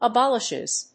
/ʌˈbɑlɪʃɪz(米国英語), ʌˈbɑ:lɪʃɪz(英国英語)/